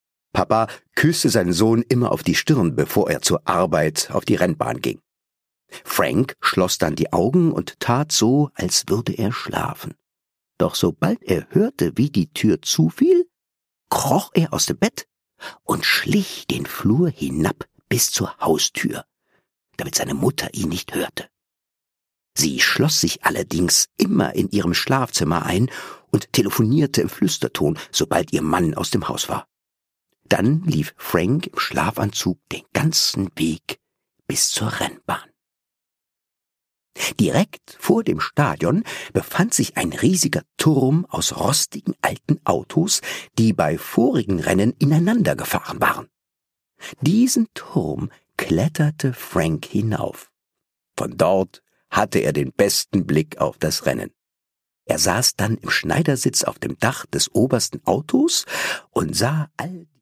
Produkttyp: Hörbuch-Download
Gelesen von: Thomas Nicolai
Er palavert, brummt und ballert, dass die Fetzen fliegen!